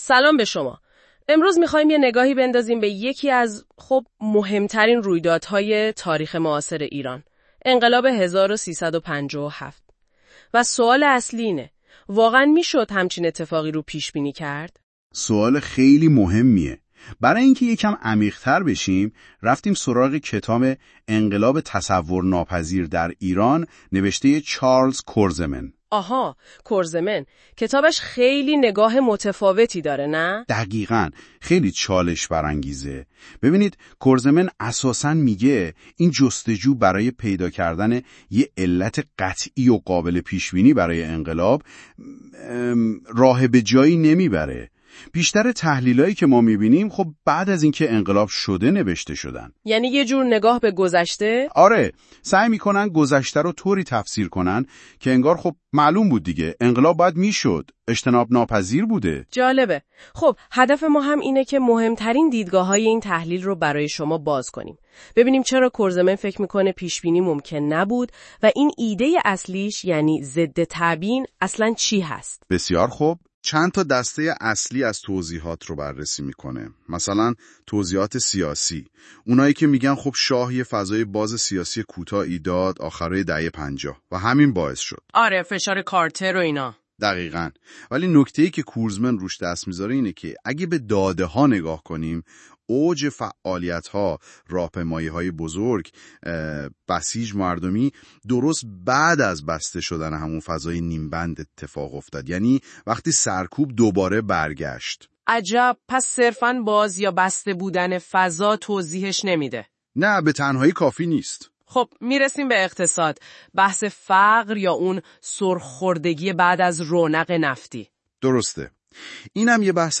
برای آشنایی بیشتر خلاصه‌ای از این کتاب به صورت پادکست (به کمک هوش مصنوعی) تهیه شده است.